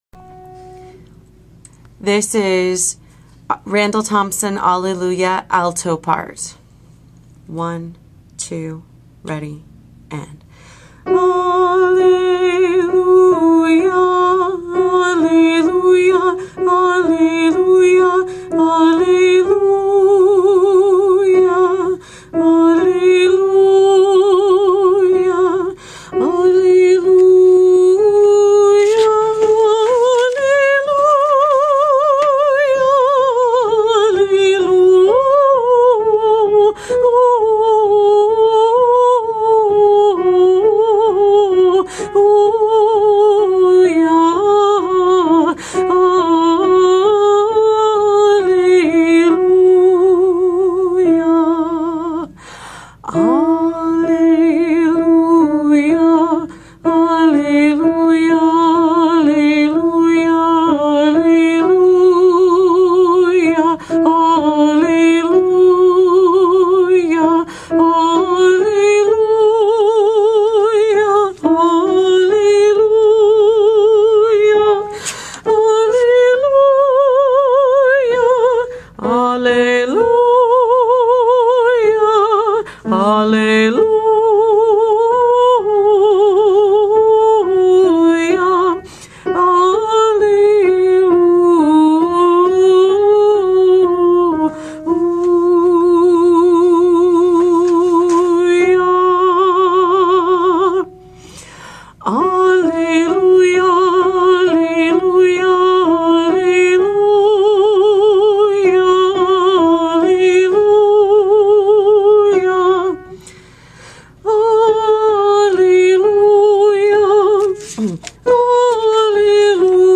MP3 versions chantées
Alto Part